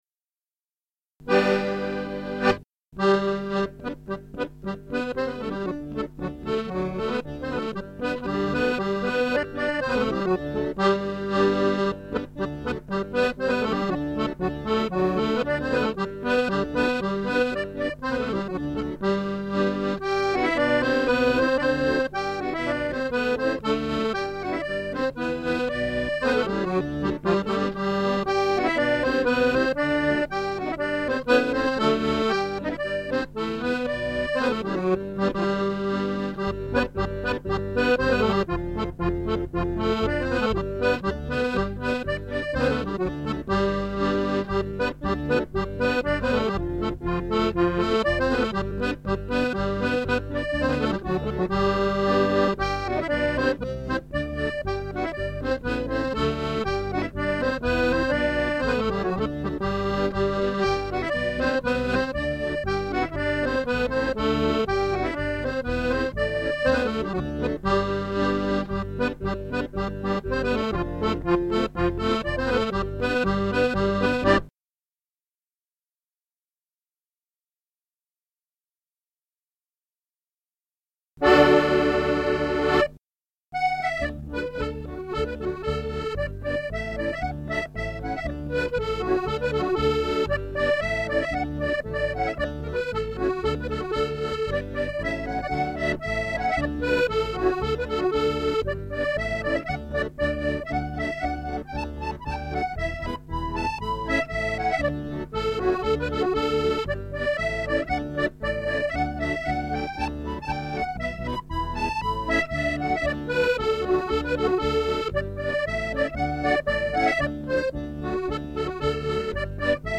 Music - 32 bar reels or jigs  + extra few bars which stop suddenly